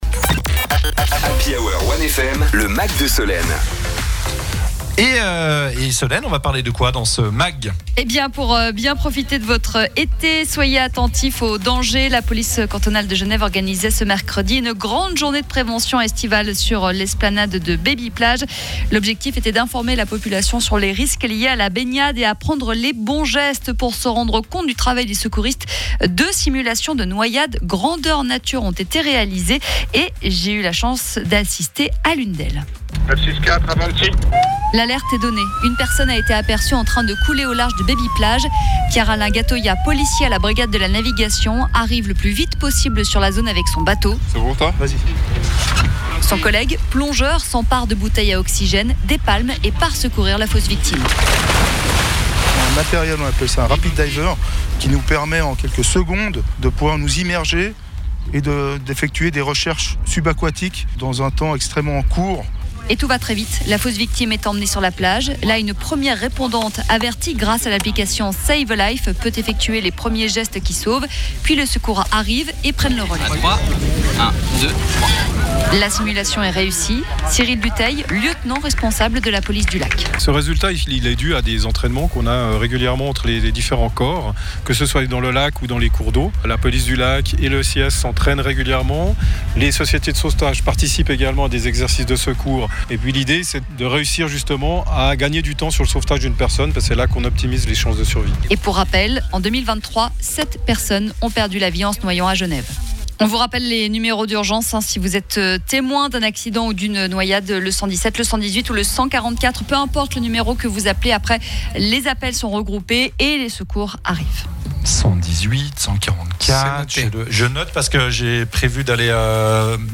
ReportageExercice secours